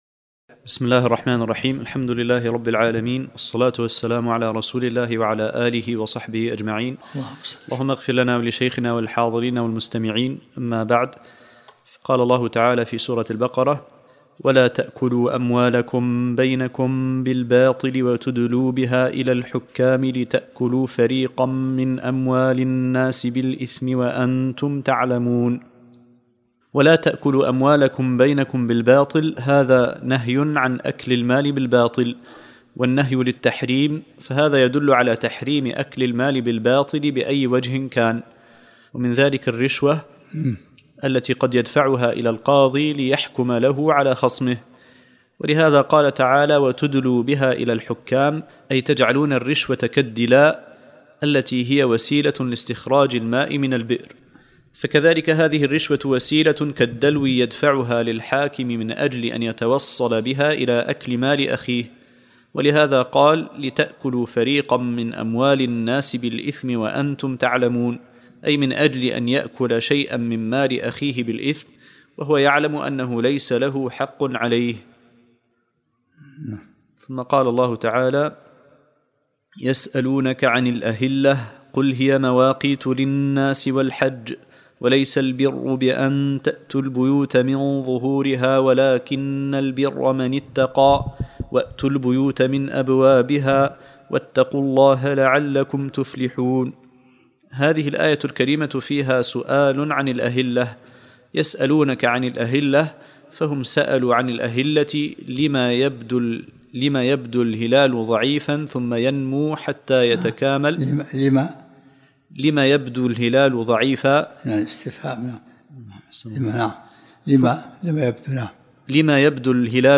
الدرس الرابع عشرمن سورة البقرة